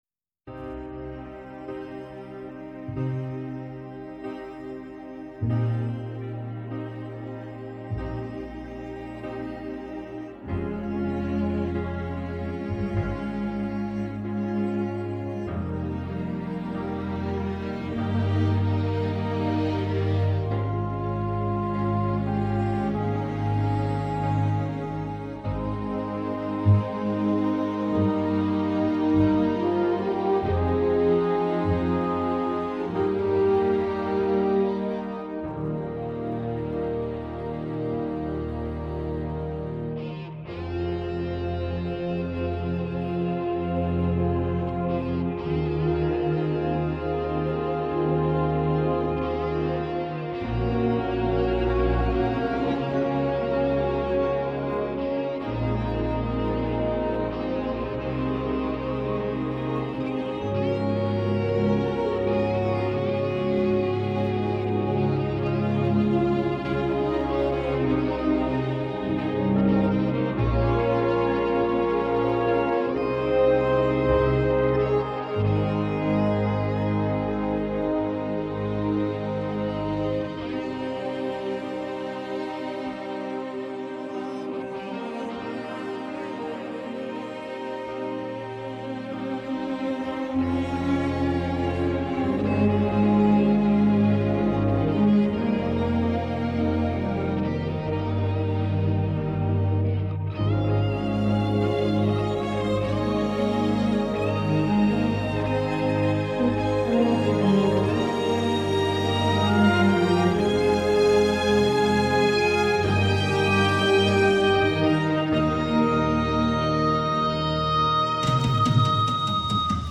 Backing track mixed from my extraction of the studio version